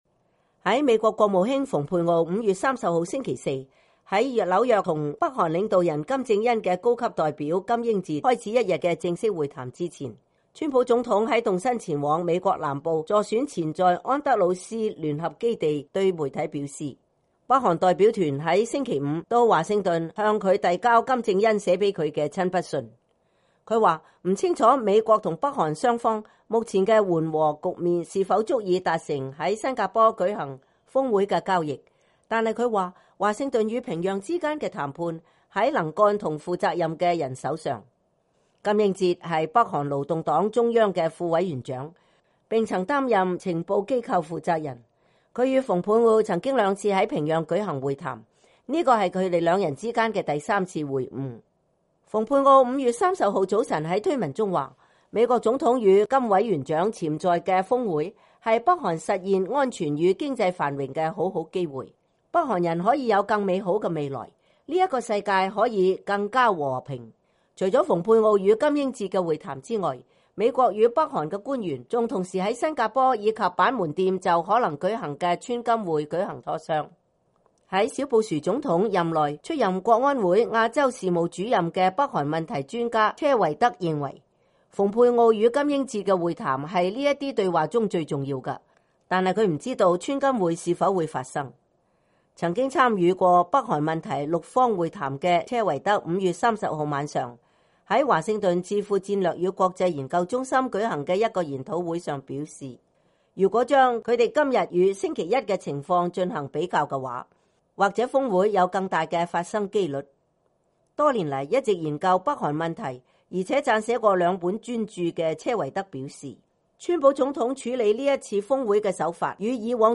曾經參與過北韓問題六方會談的車維德5月30日晚上在華盛頓智庫戰略與國際研究中心舉行的一個研討會上表示：“如果把我們今天與星期一的情況進行比較的話，也許(峰會)有更大的發生機會率。”